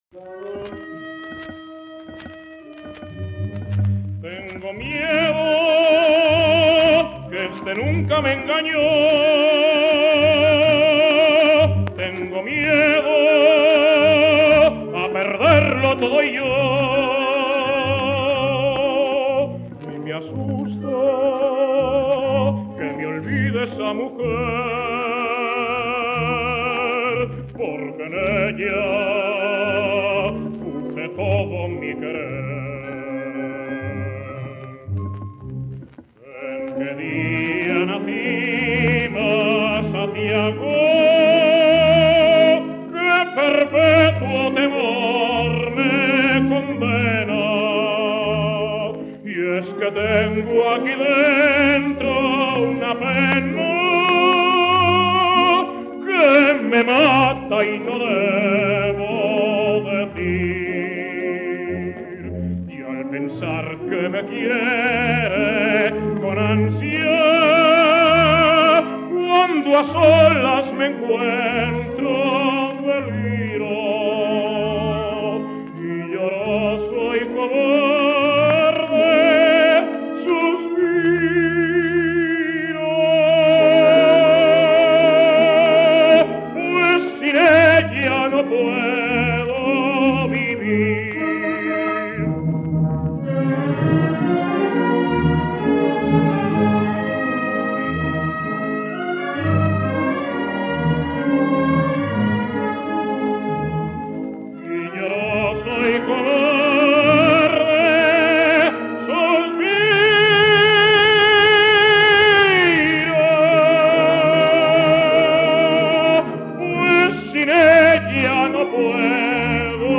orquesta [78 rpm